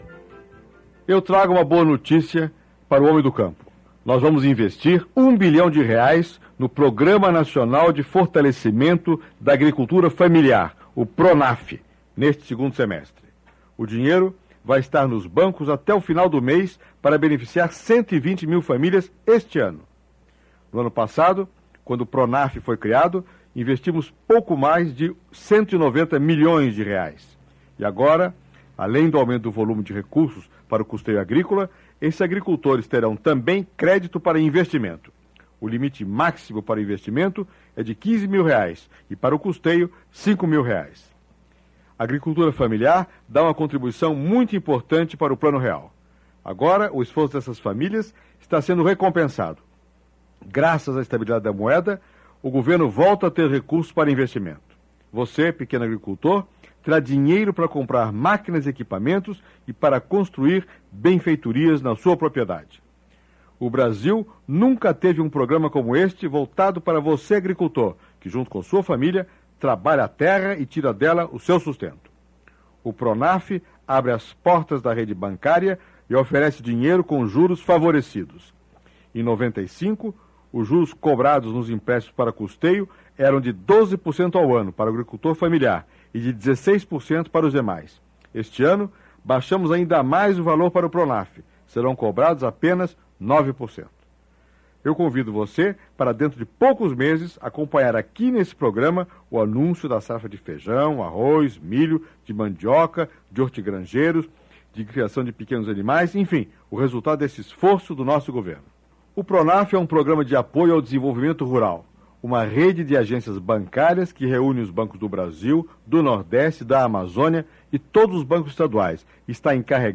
Confira a seguir fala do presidente FHC no programa A Voz do Brasil:
Áudio: Radiojornal sobre o Programa de Fortalecimento da Agricultura Familiar.